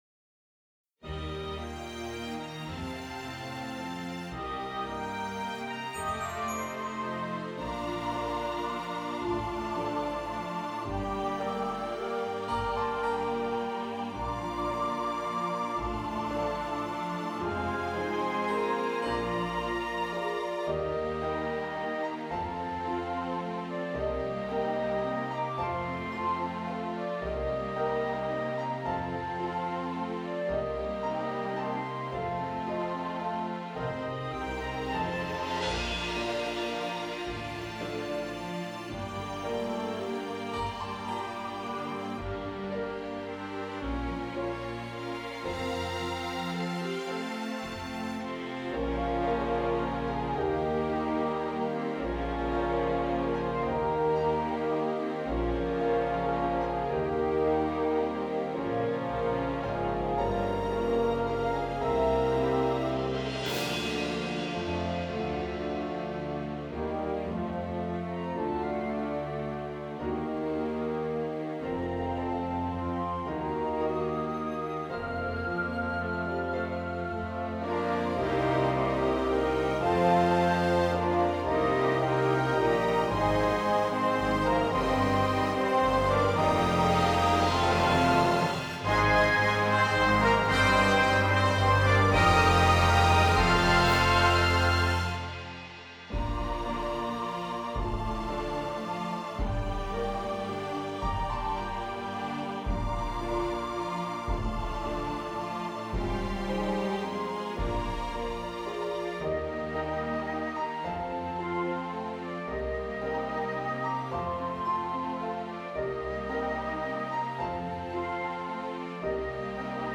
- Prosty utwór fortepianowy